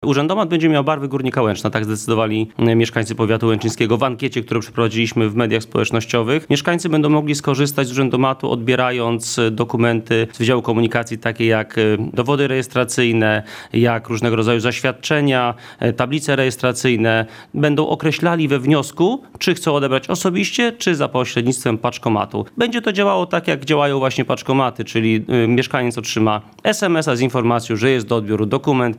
Ważne są też… jego barwy – mówi starosta łęczyński Łukasz Reszka.